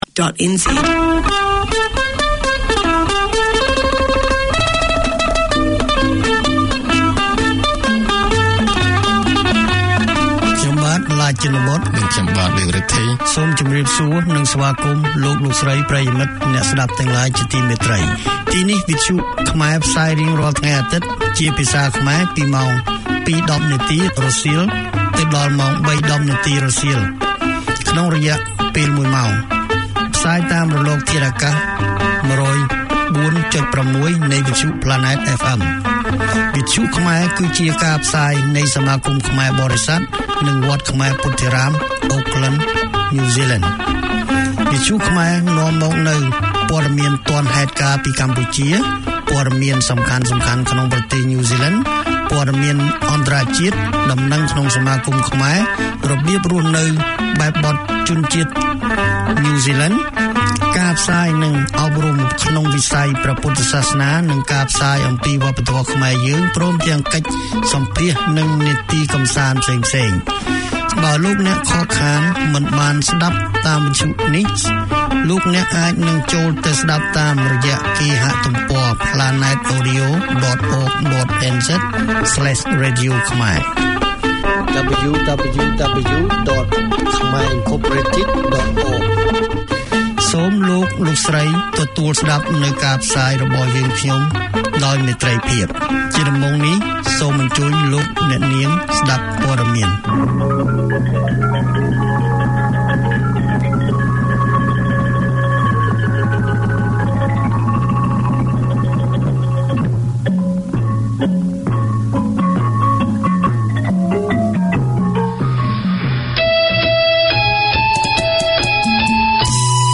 Community magazine